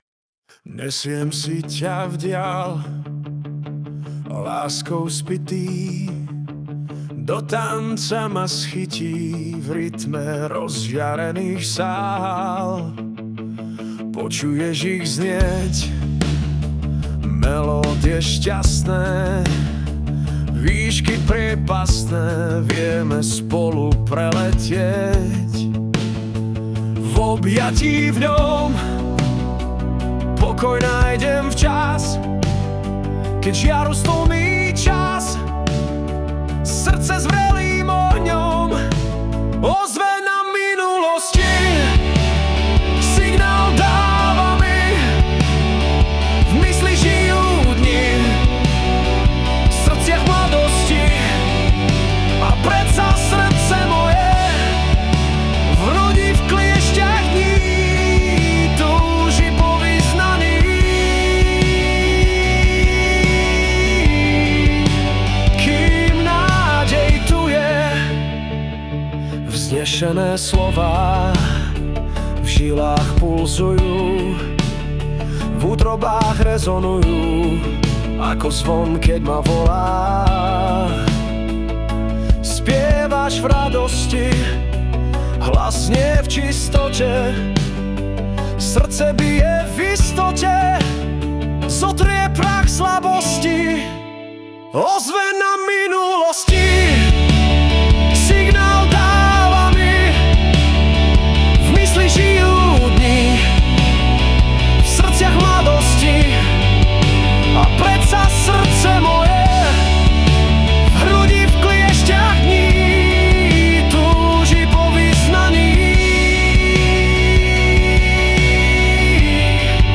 Hudba a spev AI
energická :)